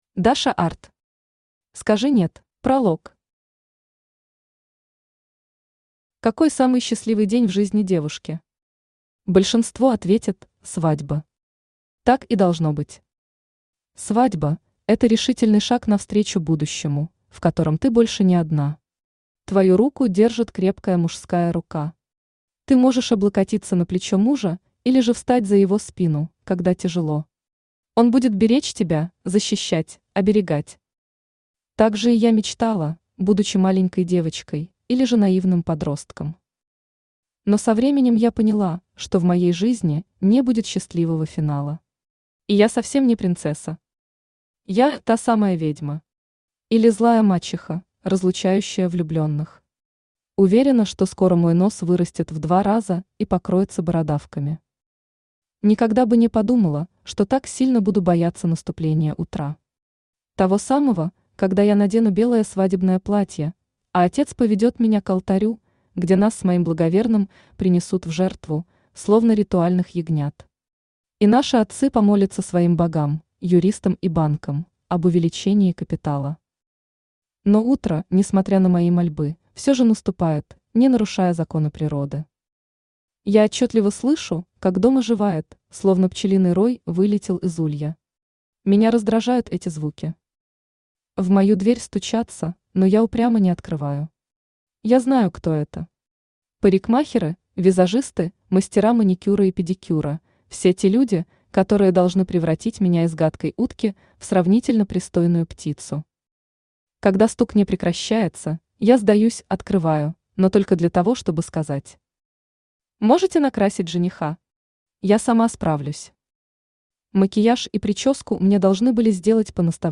Аудиокнига Скажи «нет» | Библиотека аудиокниг
Aудиокнига Скажи «нет» Автор Даша Art Читает аудиокнигу Авточтец ЛитРес.